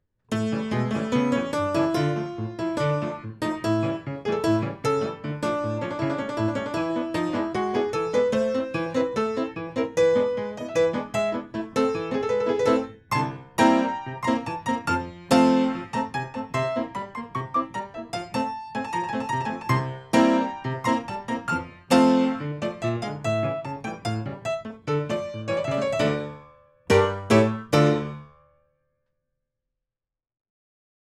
Notes: for piano
Hungarian Czardas (Dance)–B.